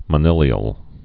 (mə-nĭlē-əl)